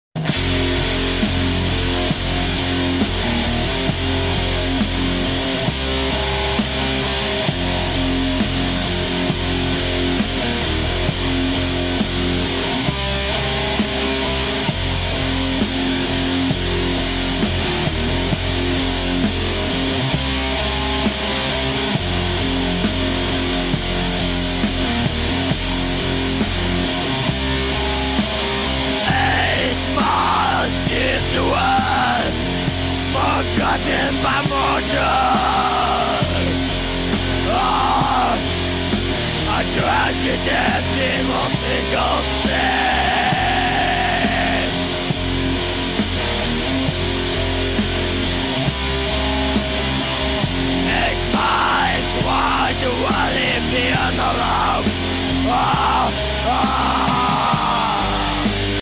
verdadero y puro Black metal